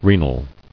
[re·nal]